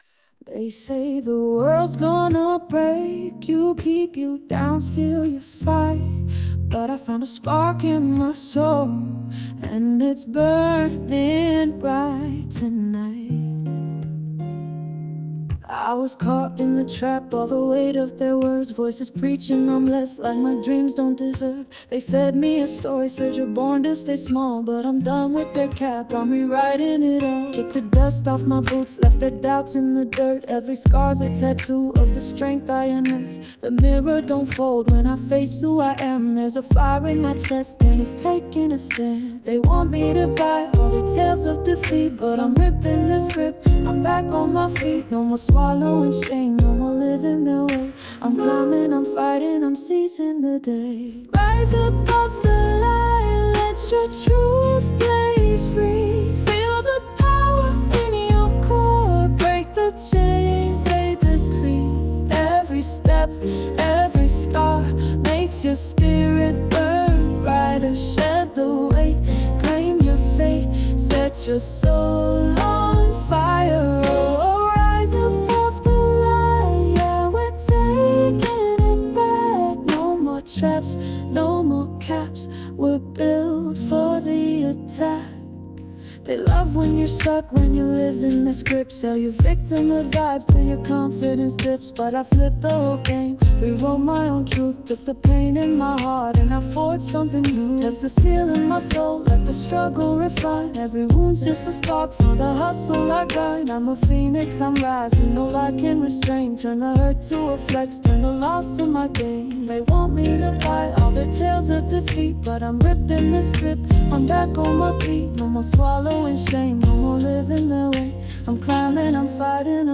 Jazz, R&B